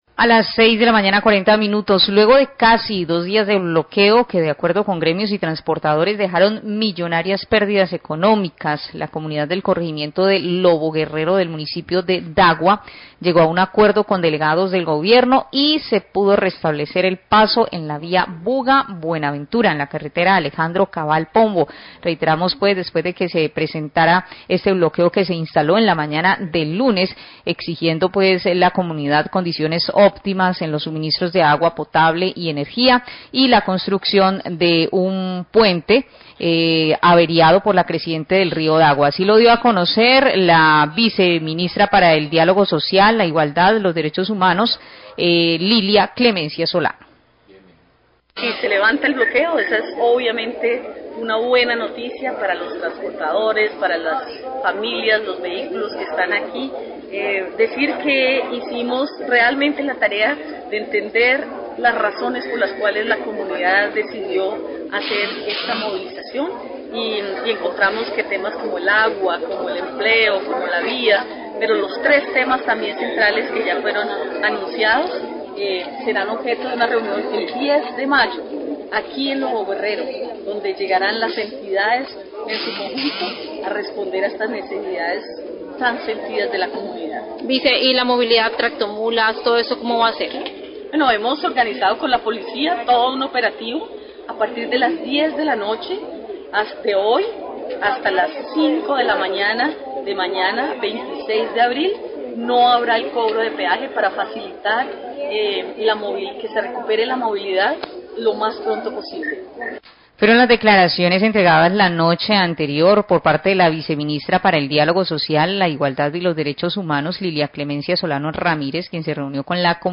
Radio
Luego de casi dos días de bloqueo de la vía por la falta del servicio de energía, deficiencias de agua potable y promesas incumplidas sobre atención por ola invernal, la comunidad protestante se llegó a un acuerdo con el gobierno nacional. Habla la viceministra para el diálogo social, Lilia Clemencia Solano.